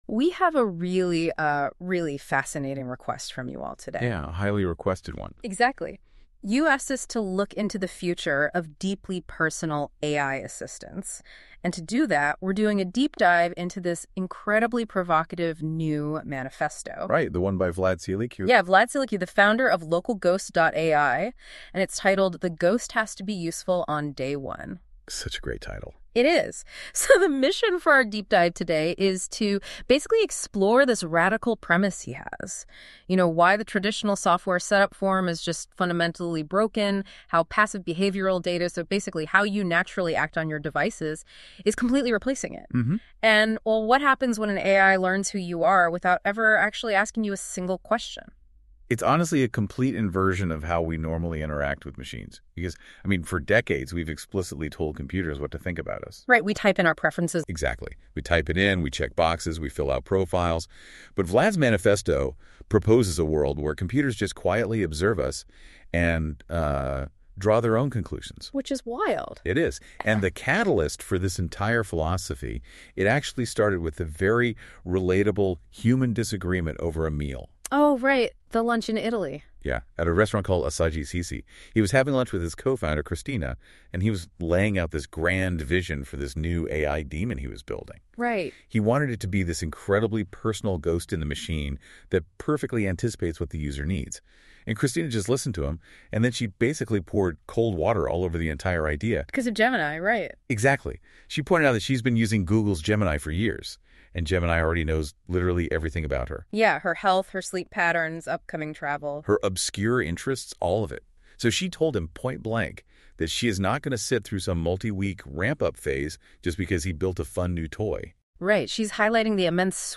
OFFLINE-READY NOTEBOOKLM AUDIO ▶ ❚❚ 00:00